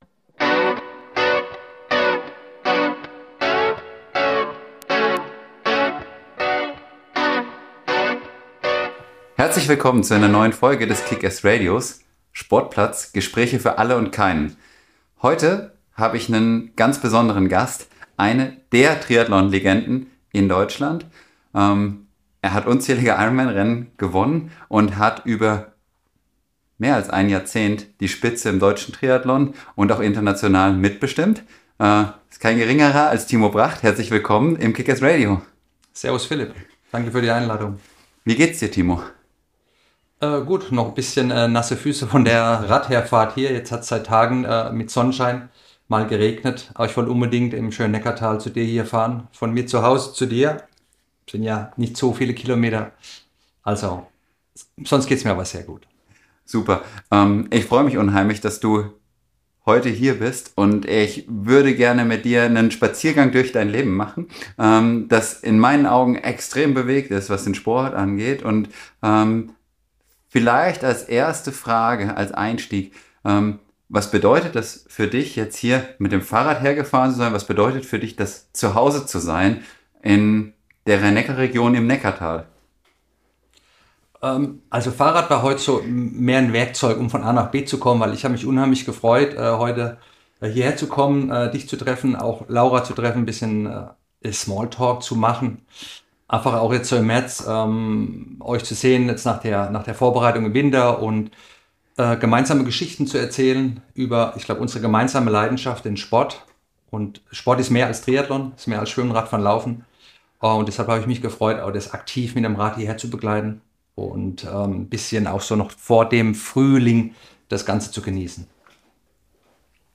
Ein tiefgehendes Gespräch über Veränderung, Sinn und die Leidenschaft, sich immer wieder neu zu erfinden – im Sport und im Leben.